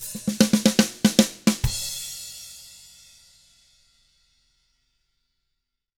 146ROCK E1-L.wav